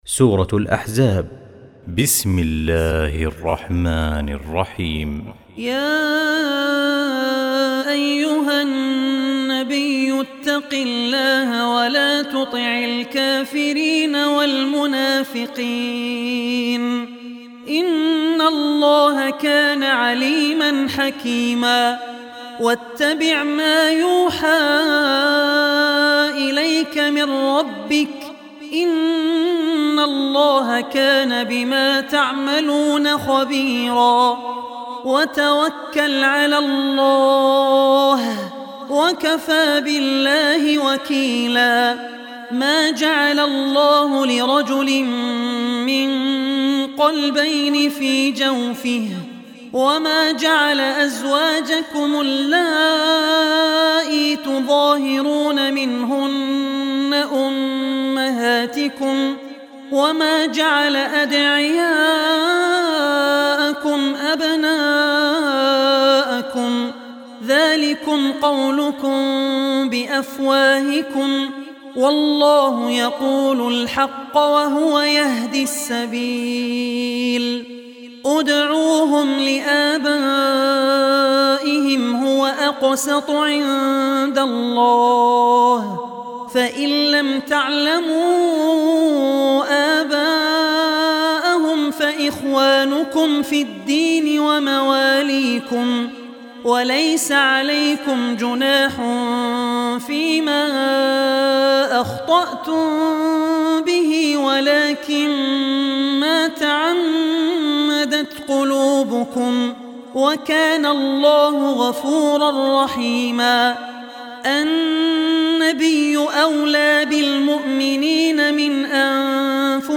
Surah Ahzab Recitation